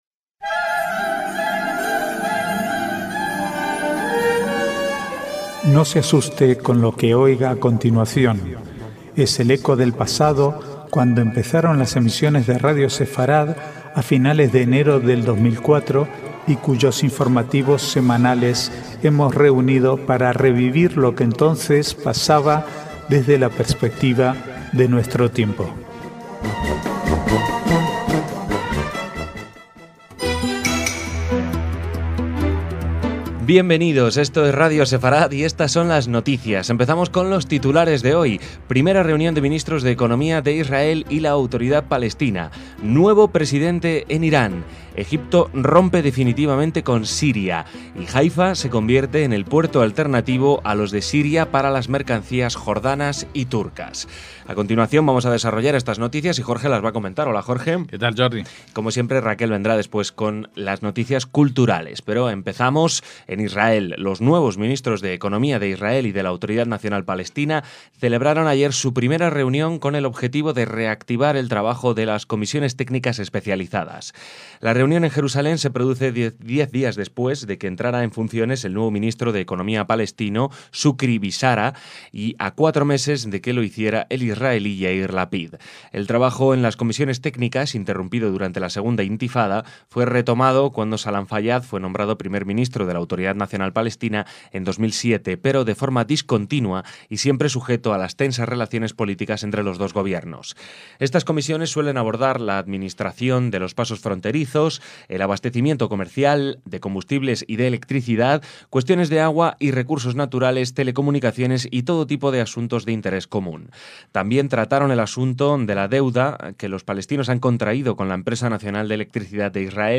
Archivo de noticias del 25 al 28/6/2013